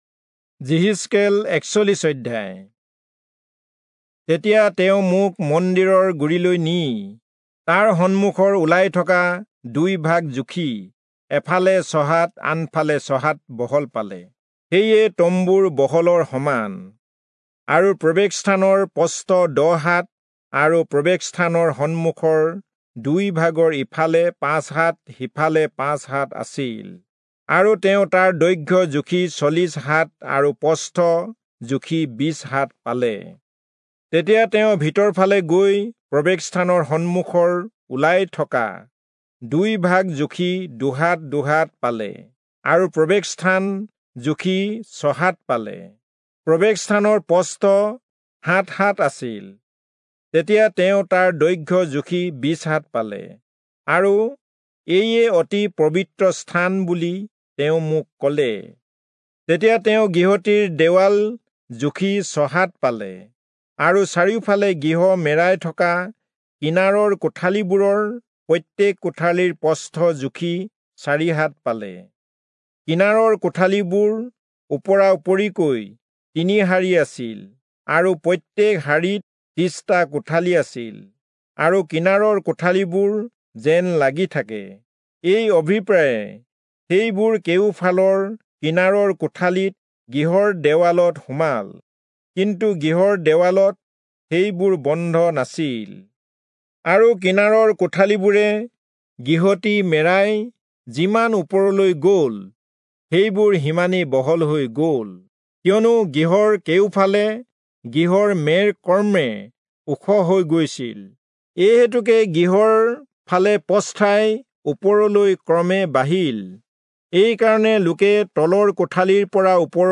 Assamese Audio Bible - Ezekiel 5 in Mrv bible version